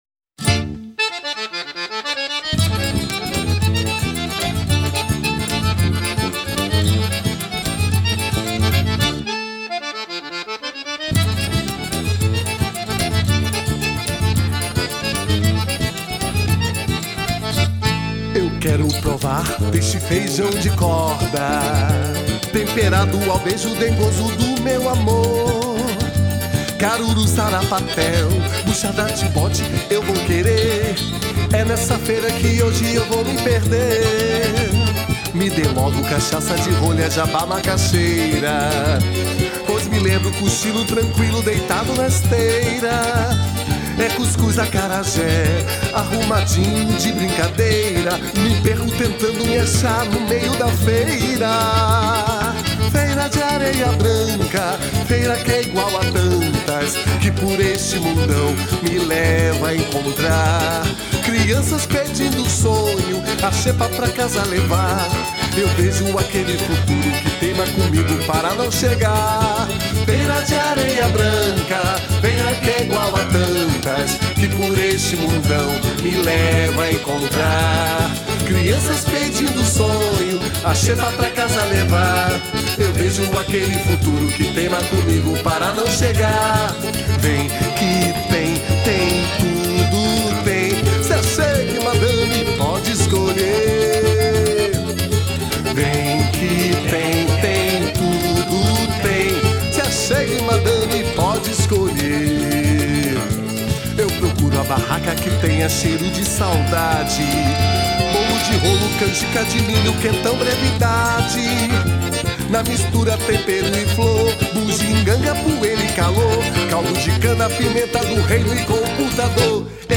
Forro Xote Forro musica nordestina